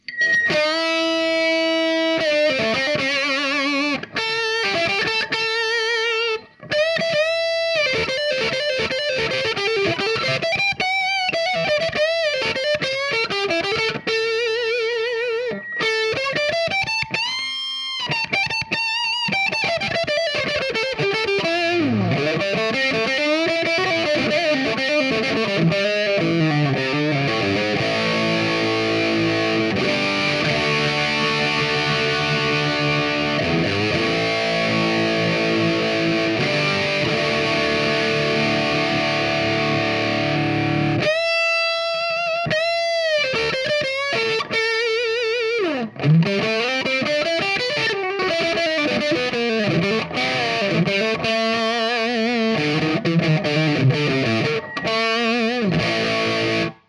Même rig encore, mais avec un Treble Booster Beano Boost de chez Analogman:
Les Paul Junior et Marshall Superlead avec treble booster.mp3